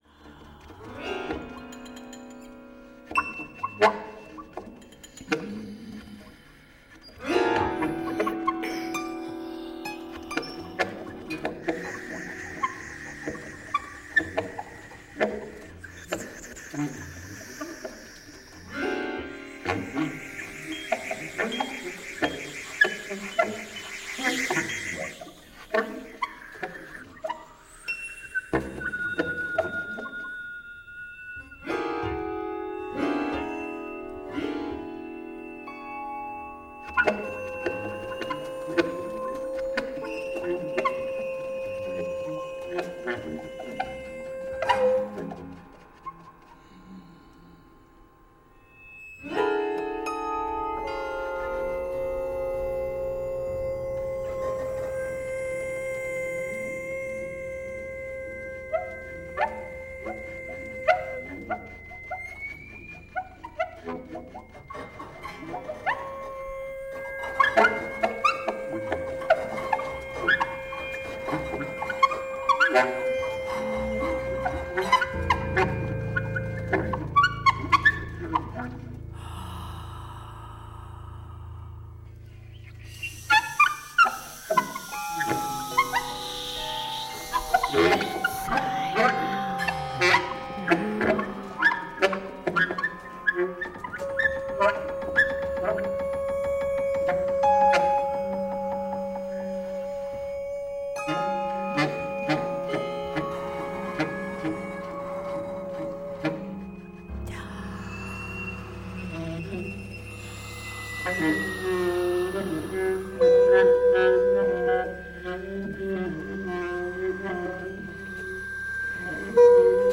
free improvised music